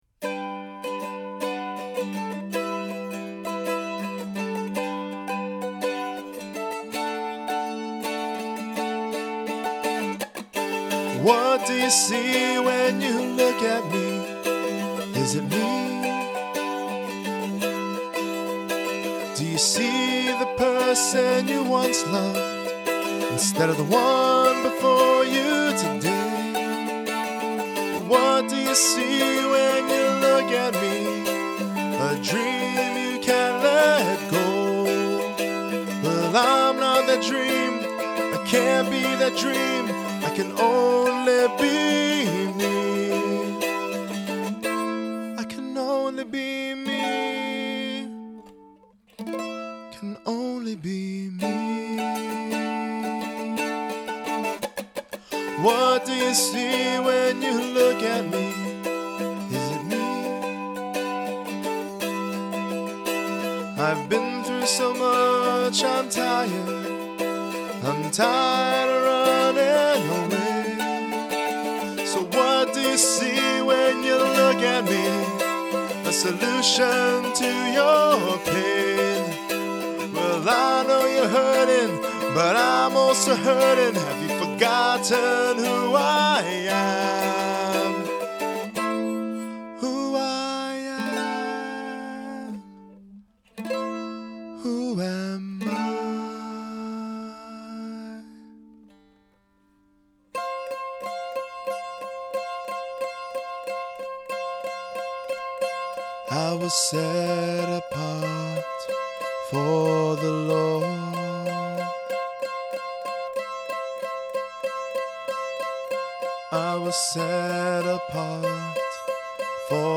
Это мандолина 1915 Gibson A1. У него отличный звук!
Я получил шанс чтобы преобразовать одну из песен, над которыми я работаю, в мандолину (
очень плохо 7:30, запись ). Как ни странно, это была песня, которую я написал во время поездки на самолете, чтобы взять мою гитару Sharp Custom :)))